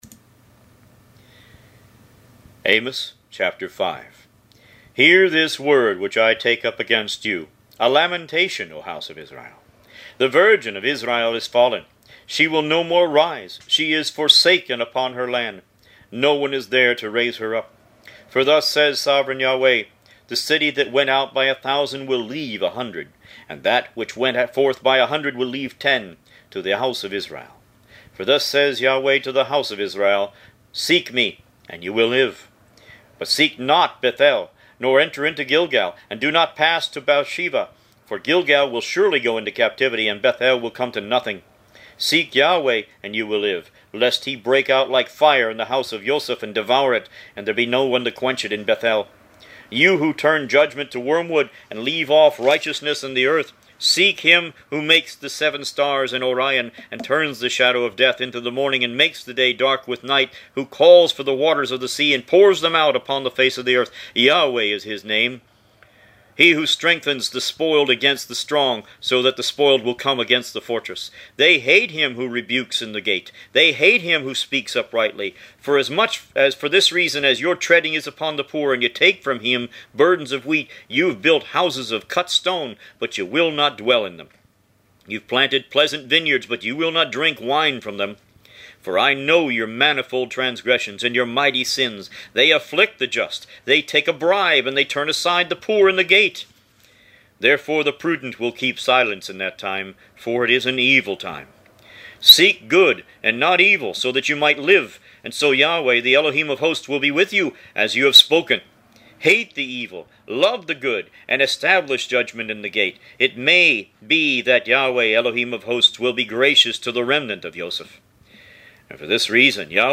Root > BOOKS > Biblical (Books) > Audio Bibles > Tanakh - Jewish Bible - Audiobook > 30 Amos